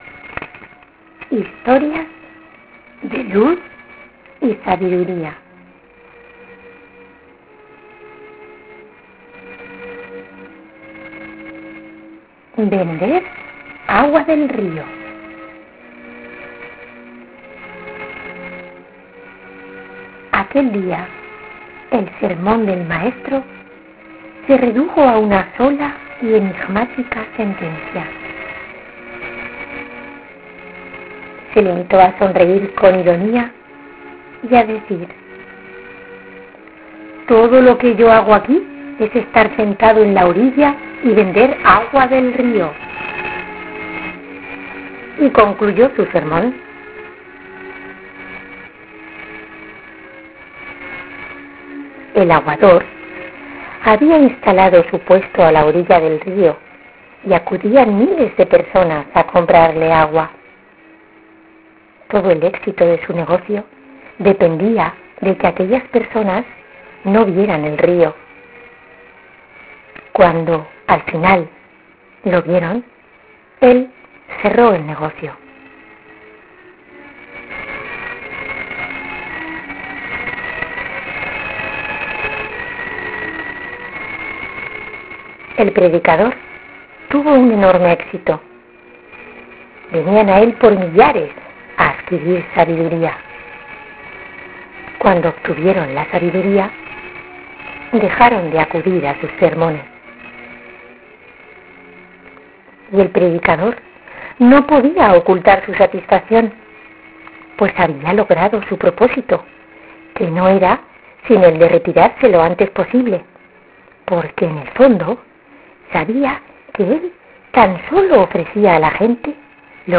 Ahora puedes bajarte esta historia narrada (656 kb)